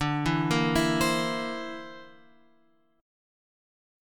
D 7th Suspended 2nd Sharp 5th